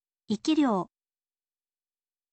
ikiryou